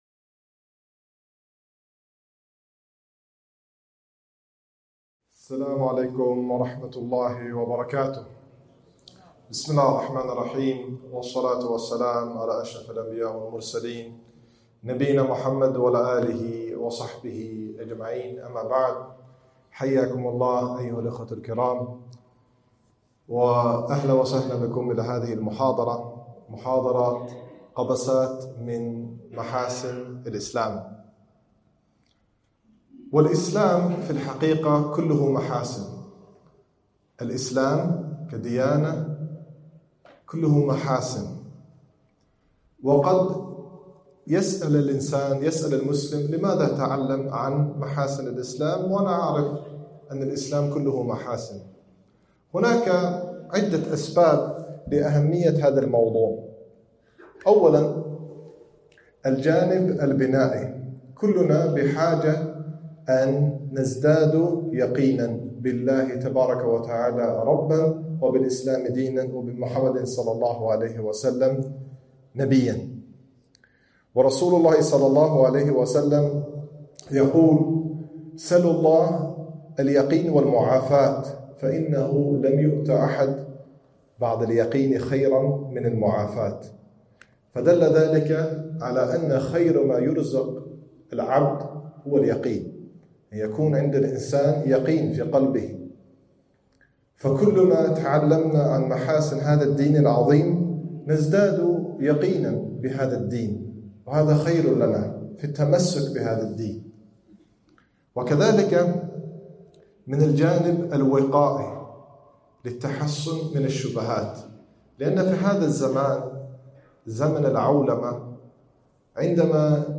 محاضرة - قبسات من محاسن الإسلام